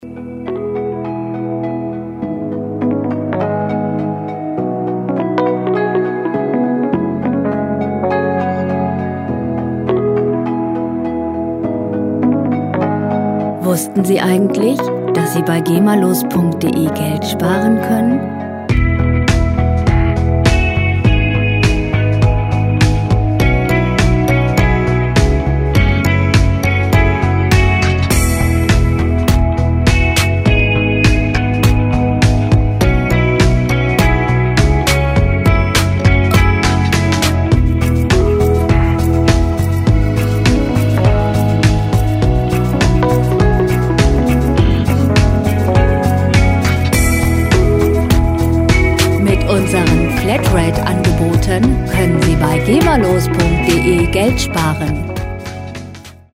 World Pop Music aus der Rubrik "Weltenbummler"
Musikstil: Indie Pop
Tempo: 102 bpm
Tonart: Es-Dur
Charakter: vital, unabhängig
Instrumentierung: E-Piano, Saxophon, E-Gitarre, Bass, Drums